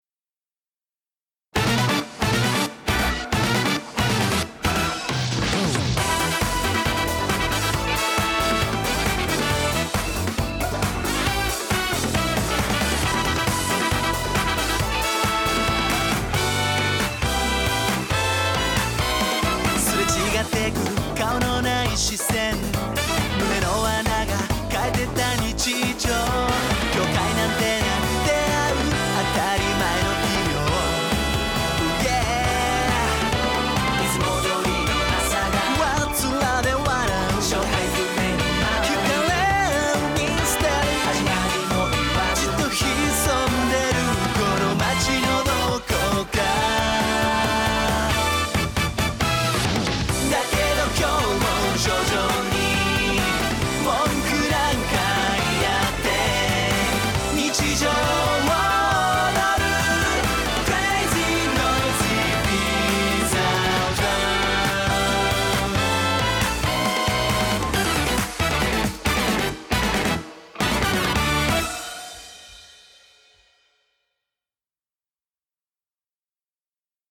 BPM136
Audio QualityPerfect (Low Quality)